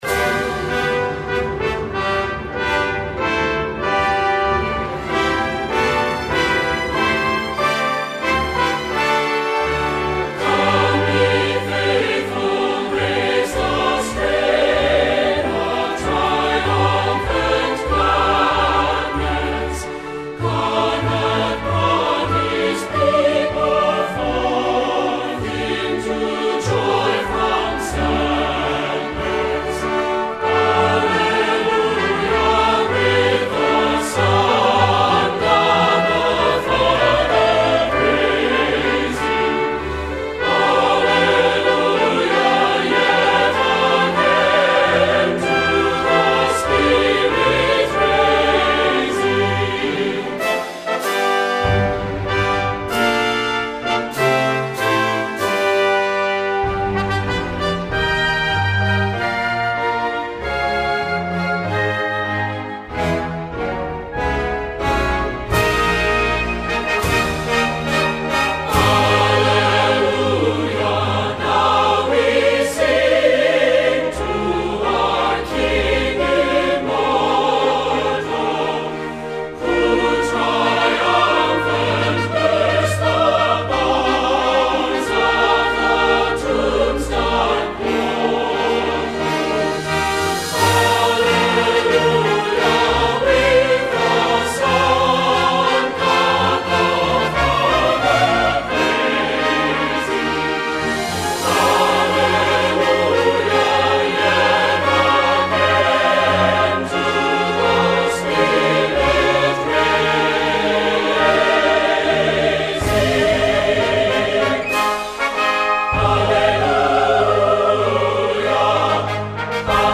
Voicing: SA/TB - Rehearsal